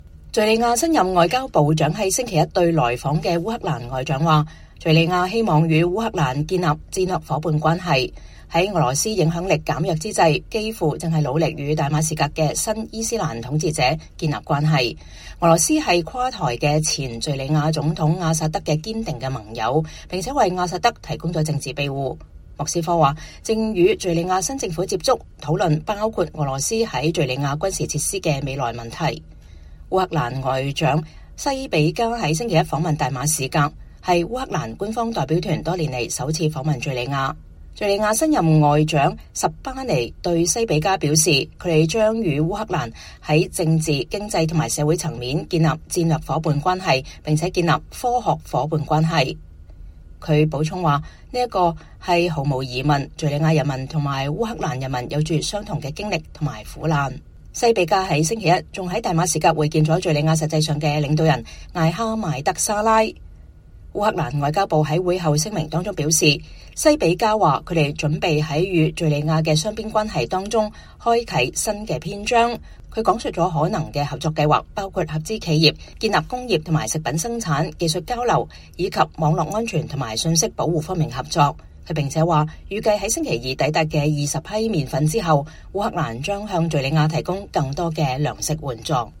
烏克蘭外長安德烈·西比加與敘利亞新任外長阿薩德·哈桑·什巴尼共同召開記者會，說明兩國強化關係的舉措。